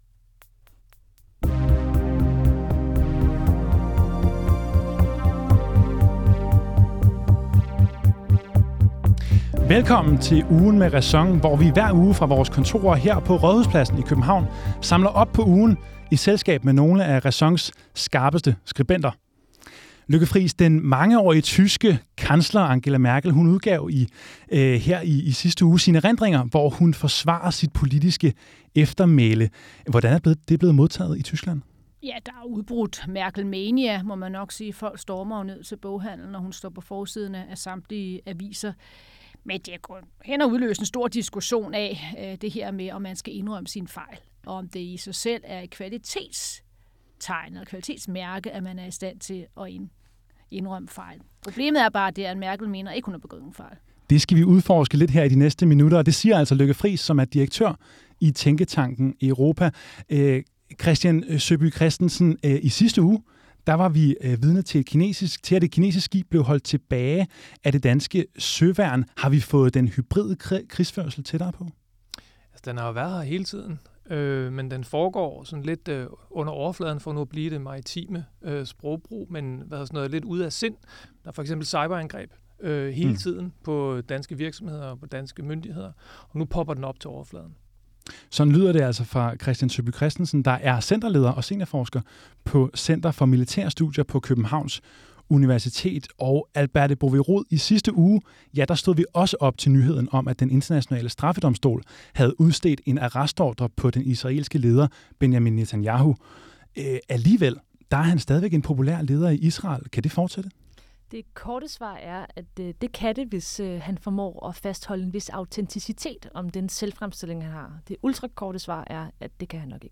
Ugen med RÆSON er vores uredigerede podcast, der hver onsdag – fra vores redaktion ved Rådhuspladsen i København – stiller tidens store spørgsmål til nogle af RÆSONs skarpeste skribenter.